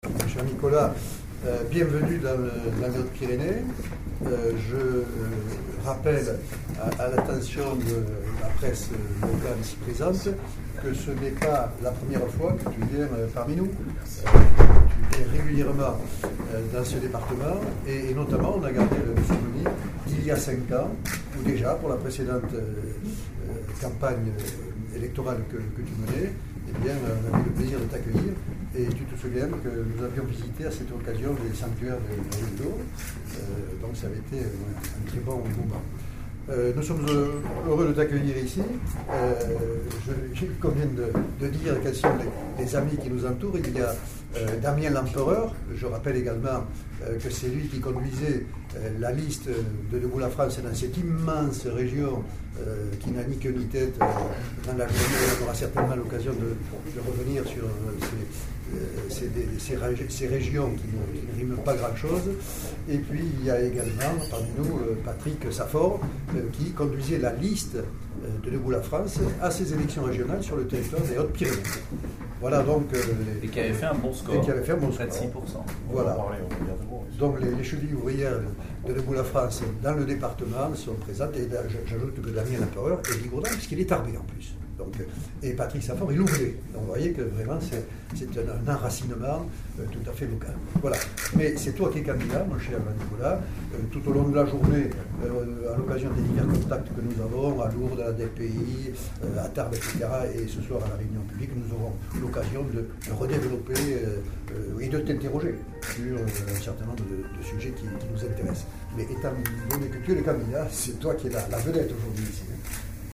La conférence de presse
A son arrivée à l’aéroport de Tarbes-Lourdes-Pyrénées, Nicolas Dupont-Aignan a tenu une conférence de presse dans une salle de l’aérogare d’affaires.